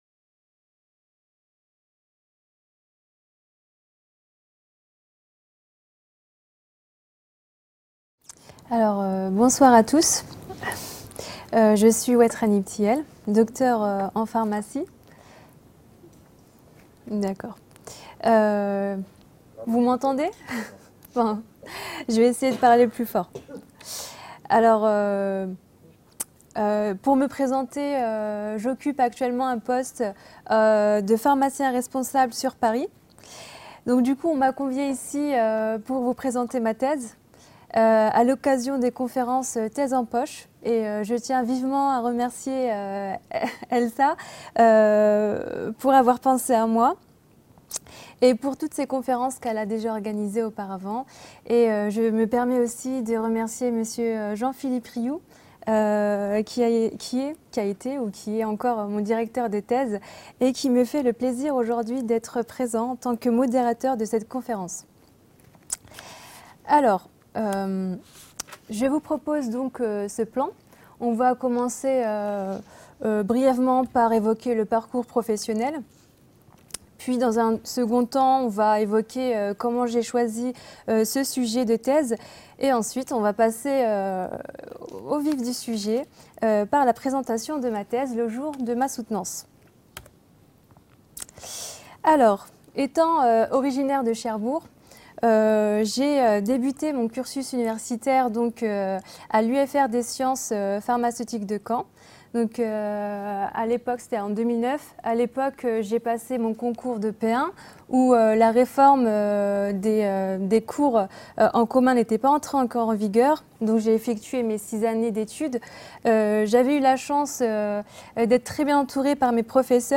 Les conférences santé de la BU